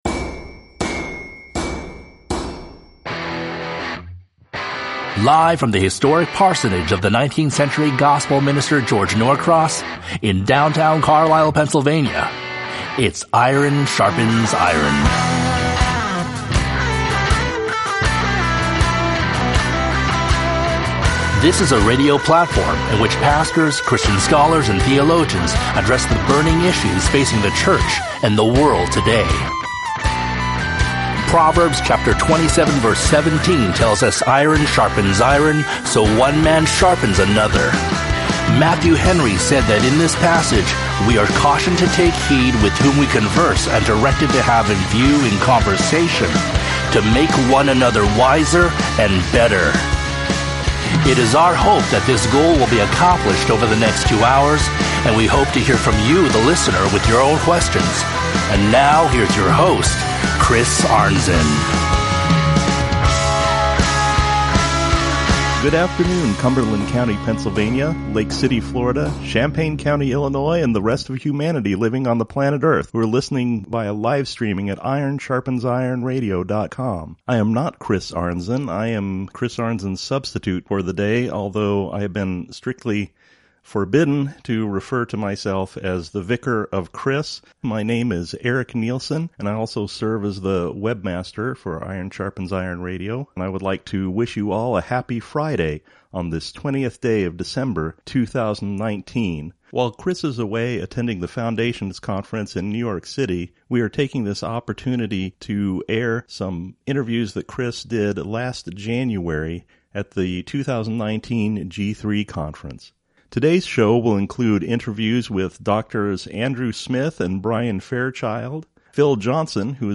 Interviews recorded LIVE at the 2019 G3 CONFERENCE!!!